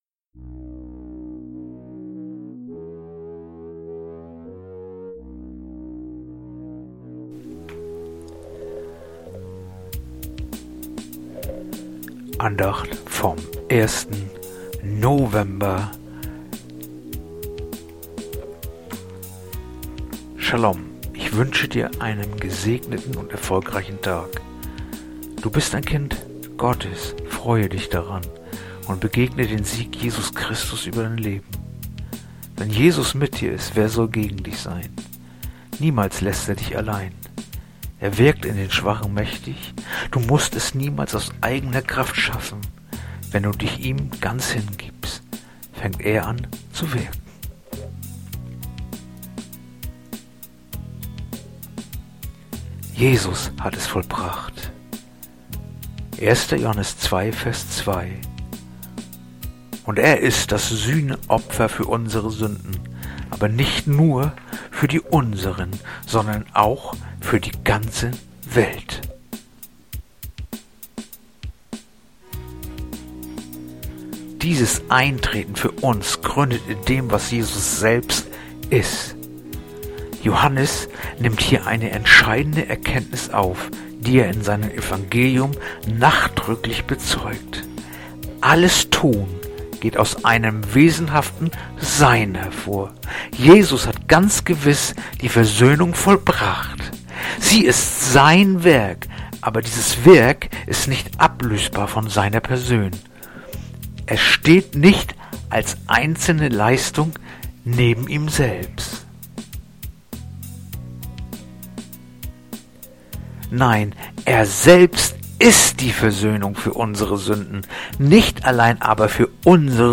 heutige akustische Andacht
Andachten-vom-01-November-1-Johannes-2-2.mp3